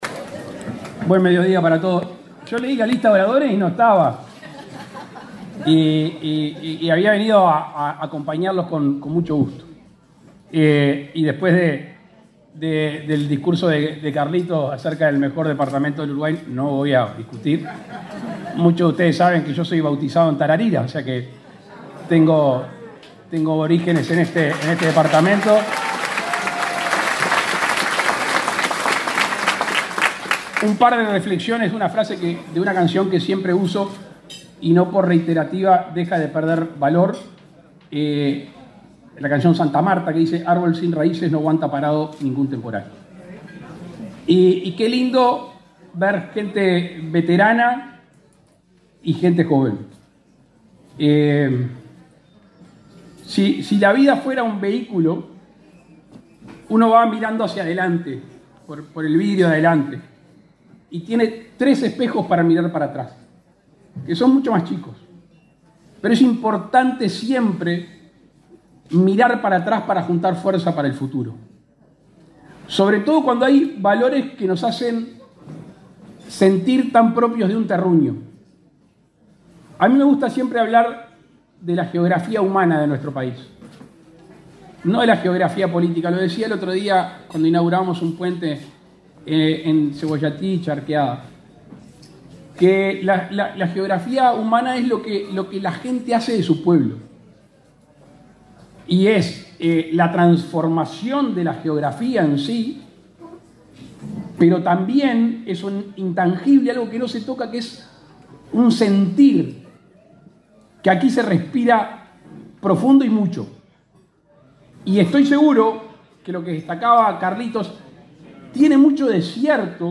Palabras del presidente Luis Lacalle Pou
El presidente de la República, Luis Lacalle Pou, participó, este sábado 6, en la Fiesta Suiza, en la localidad de Nueva Helvecia, departamento de